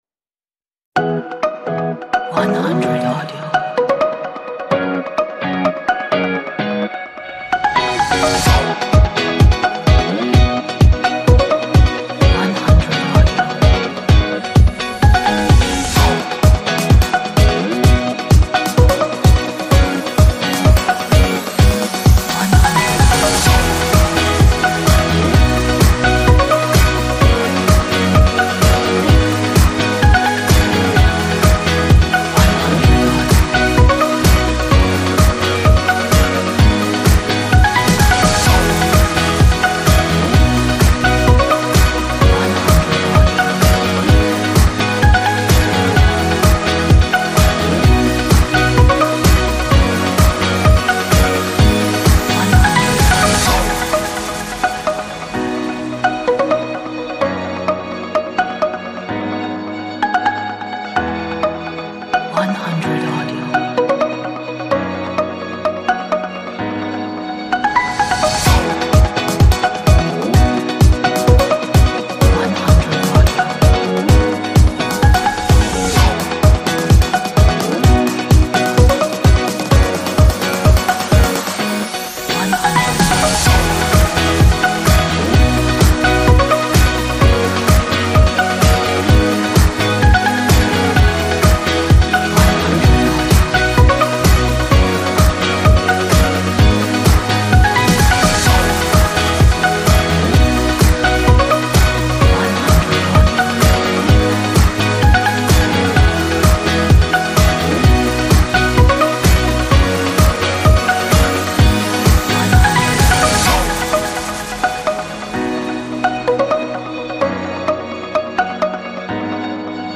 这是一首充满活力和快乐的心情的鼓舞人心的背景音乐。